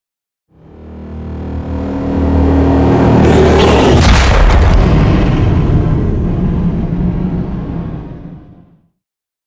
Dramatic electronic whoosh to hit trailer
Sound Effects
Fast paced
In-crescendo
Atonal
dark
futuristic
intense
tension
woosh to hit